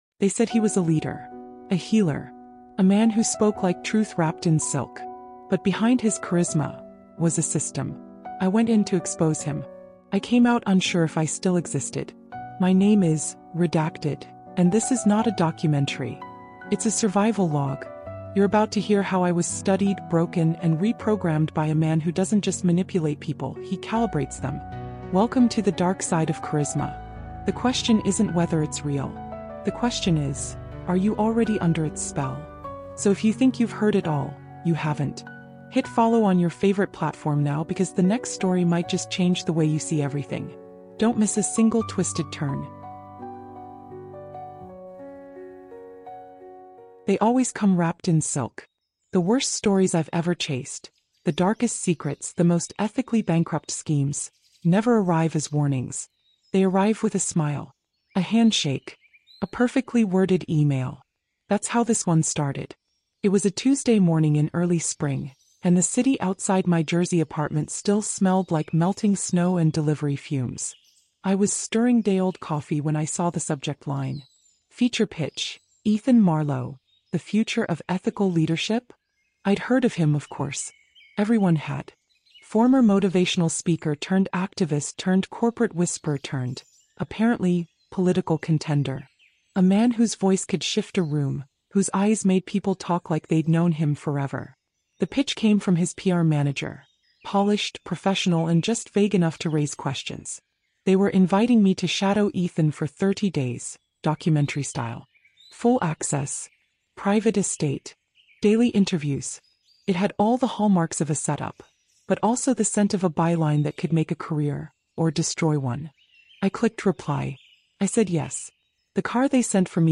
This isn’t just another true crime or dark fiction story — it’s a first-person psychological thriller series exposing how persuasion, communication tactics, and body language can become tools of covert manipulation. Narrated by a seasoned investigative journalist, this gripping narrative follows a trail of influence, obedience loops, and behavioral control inside a luxurious New Jersey estate.